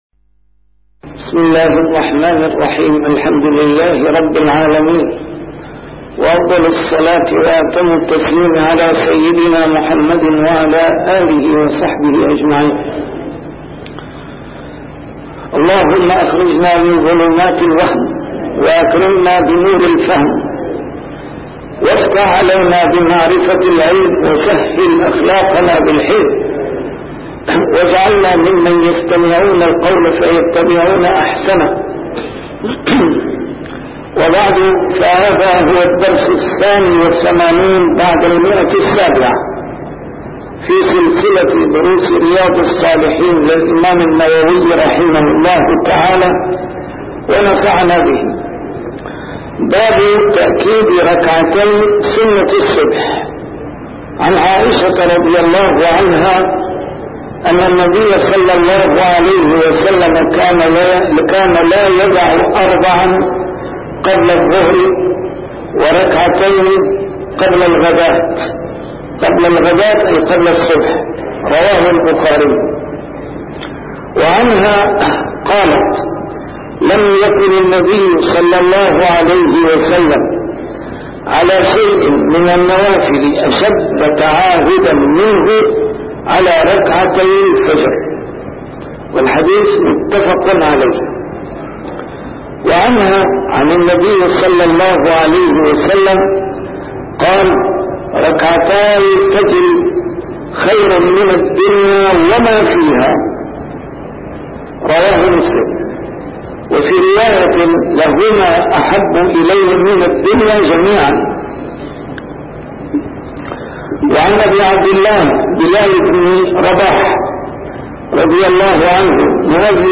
A MARTYR SCHOLAR: IMAM MUHAMMAD SAEED RAMADAN AL-BOUTI - الدروس العلمية - شرح كتاب رياض الصالحين - 782- شرح رياض الصالحين: تأكيد ركعتي سنة الصبح، تخفيف ركعتي الفجر (؟)